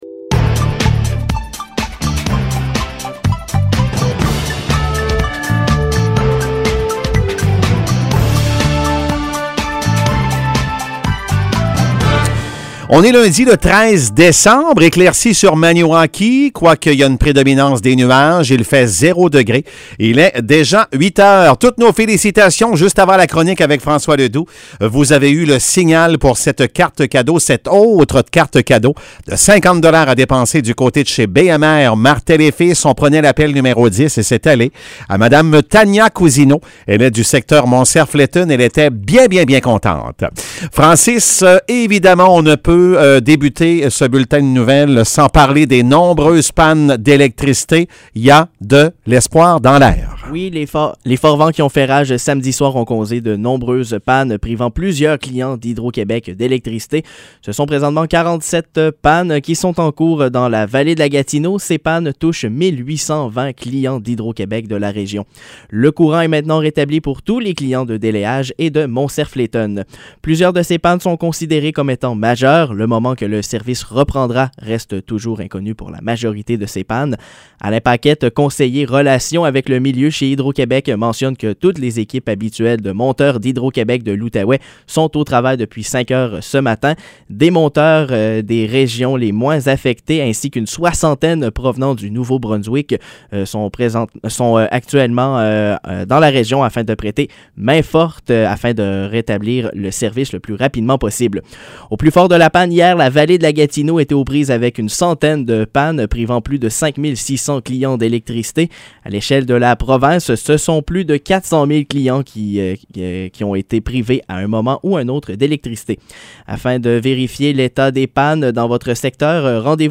Nouvelles locales - 10 décembre 2021 - 8 h